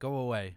Voice Lines / Dismissive
go away.wav